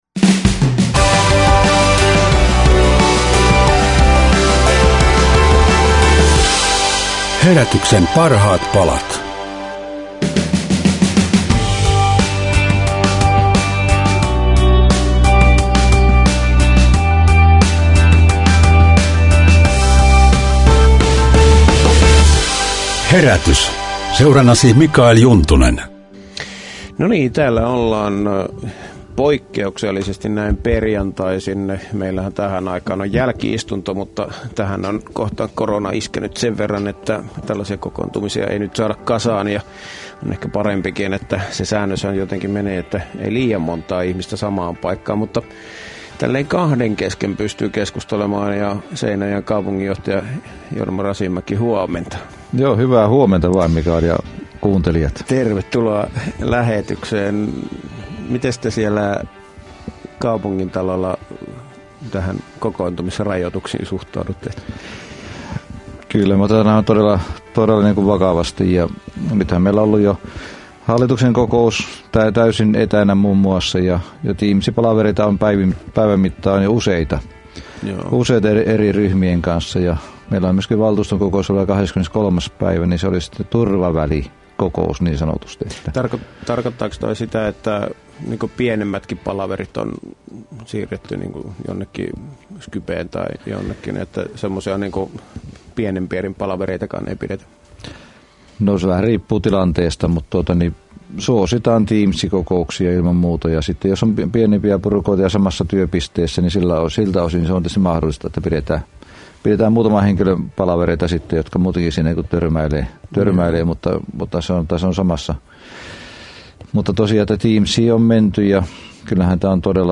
Seinäjoen kaupunginjohtaja päivitti eteläpohjalaiset korona-ajan tuntemukset Radio Dein Herätys!-aamulähetyksessä.